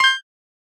experience_gained_3.ogg